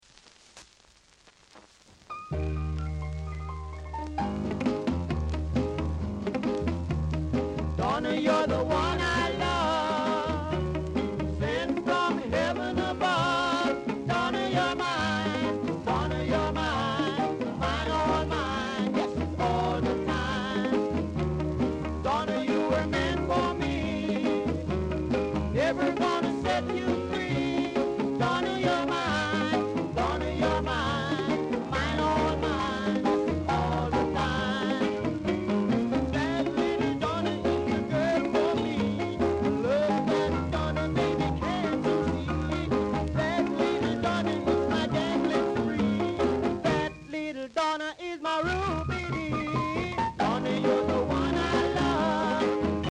Ska Vocal Group
Re-press. great ska vocal!